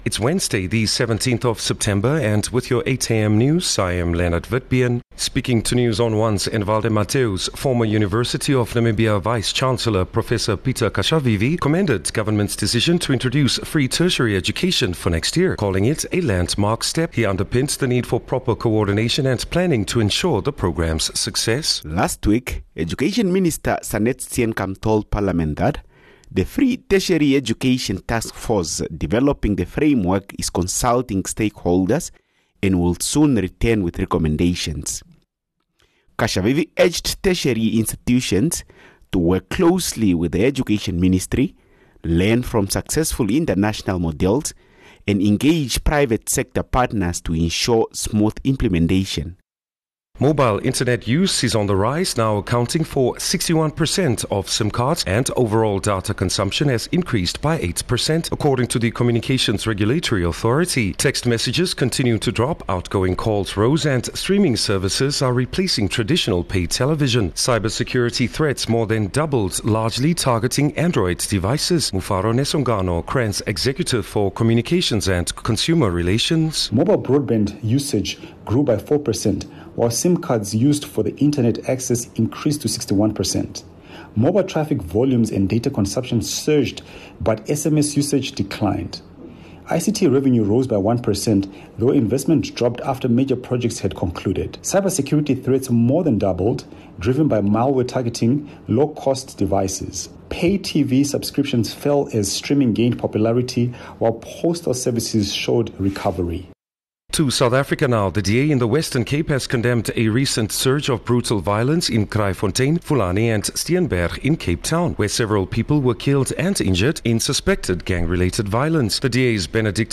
17 Sep 17 September-8am news